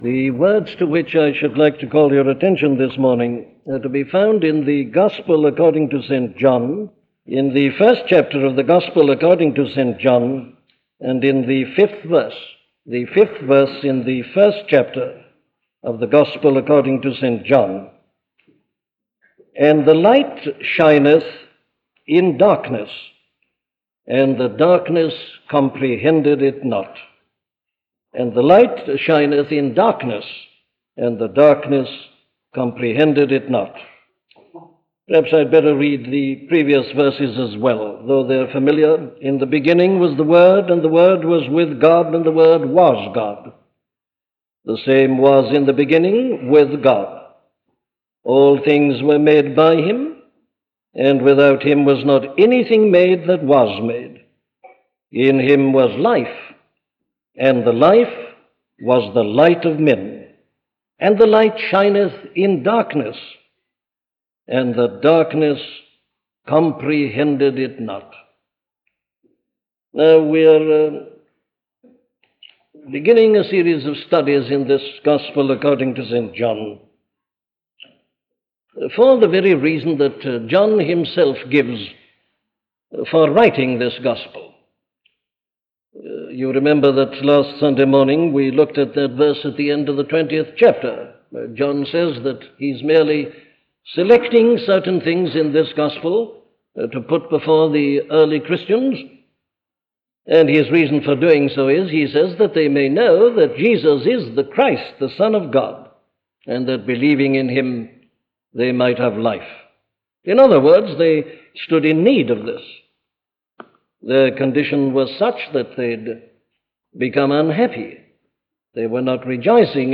The Light Shines in the Darkness - a sermon from Dr. Martyn Lloyd Jones